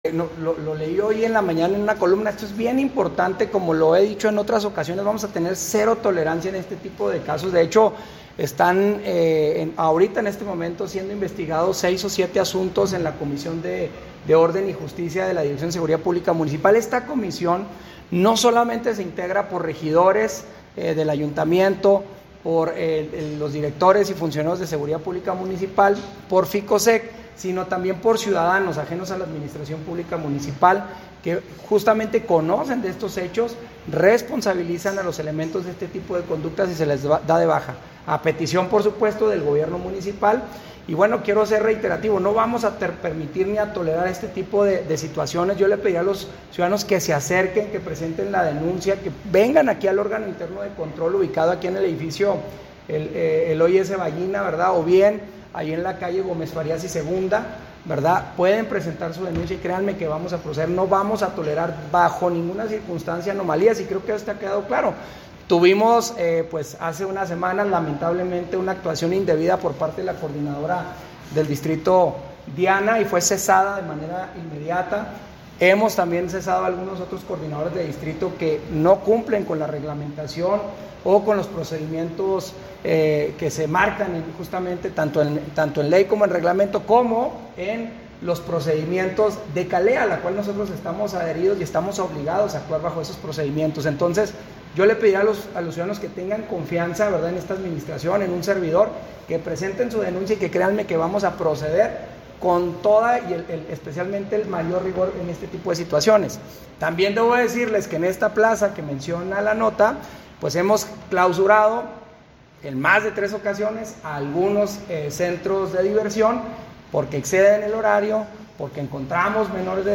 (Audio) Marco Antonio Bonilla Mendoza, Presidente Municipal de Chihuahua